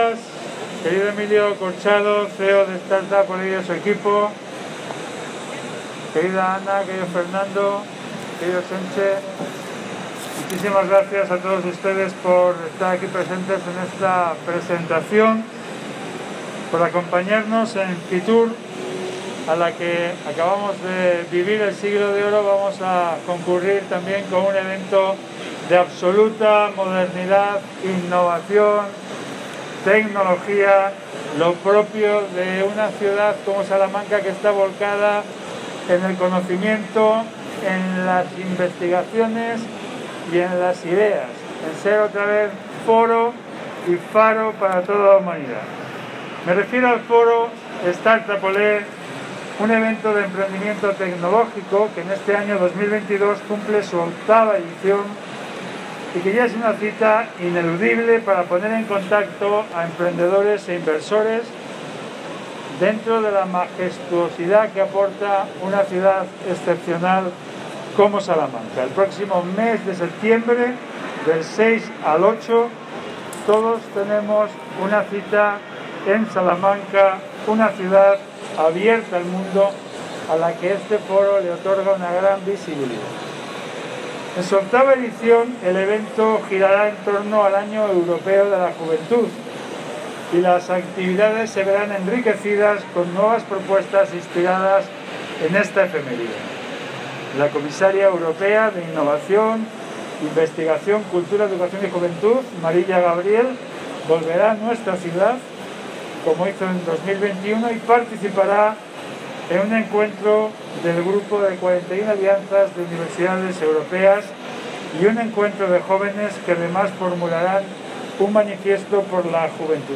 Estas son algunas de las palabras del alcalde salmantino anunciando la presentación de Start Up Olé en Fitur.